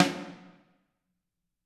Index of /90_sSampleCDs/Bob Clearmountain Drums I/Partition G/Samples/VOLUME 006